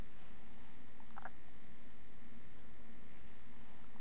Det är det enda ljud man hör förutom bruset.
Är som mitt emellan ett klonkande och ett slafsande läte.
Nja, jag tycker det låter som när man tuggar tuggummi och det liksom släpper från tanden. Tjoff liksom med ett sugande ljud.
Det är mer klonk i det i verkligheten. Spelade in med mp3-spelaren bara.
armljud.mp3